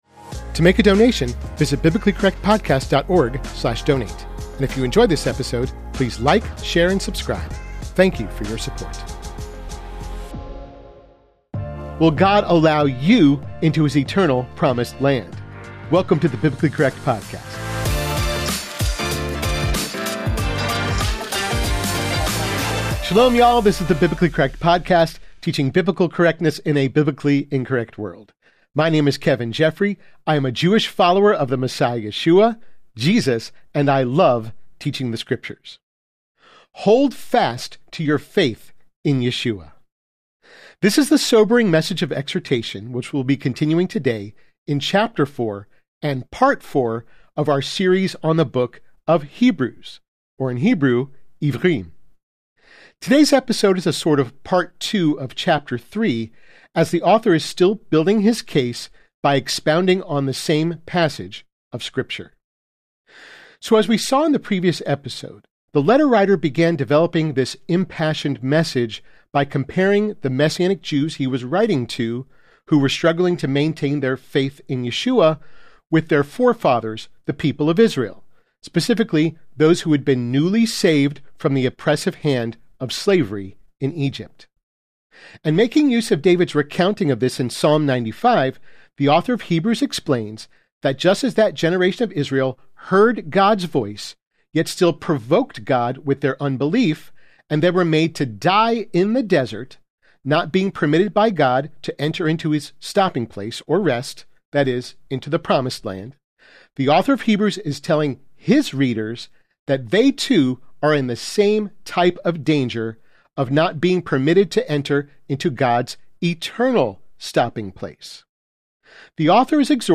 and Messianic Jewish Teacher .